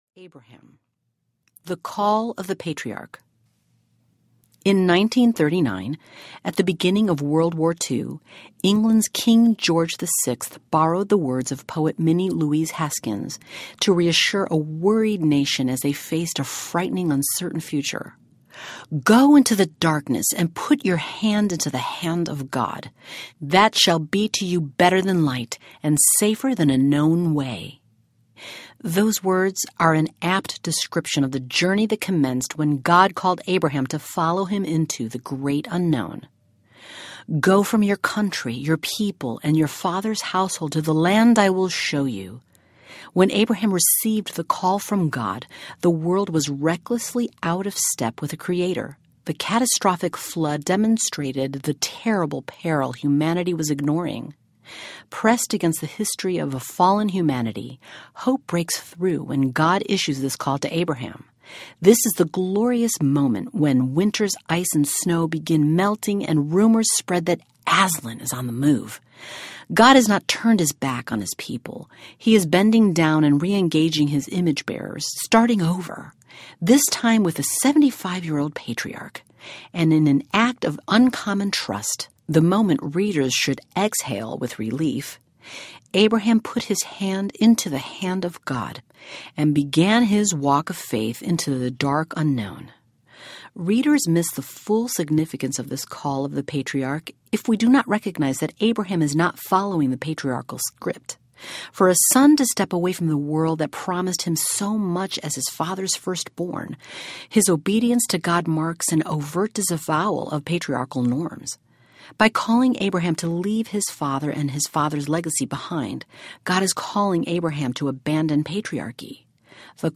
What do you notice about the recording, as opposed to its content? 6.18 Hrs. – Unabridged